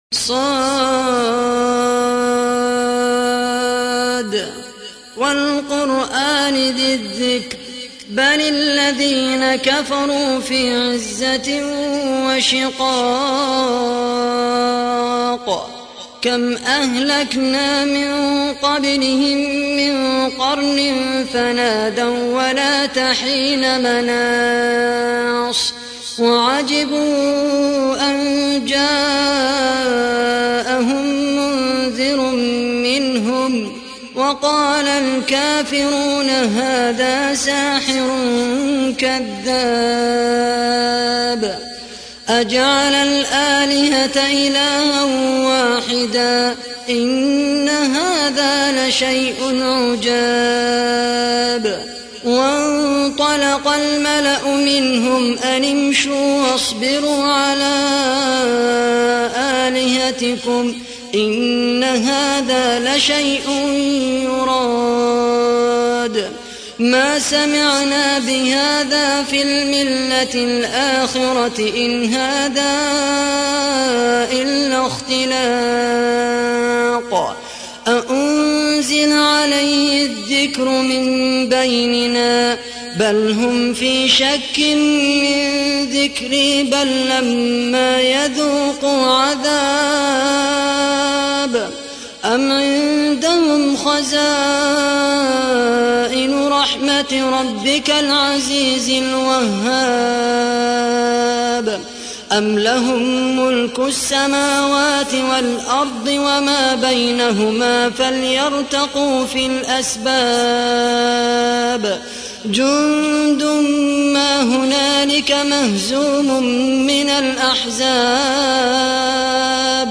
تحميل : 38. سورة ص / القارئ خالد القحطاني / القرآن الكريم / موقع يا حسين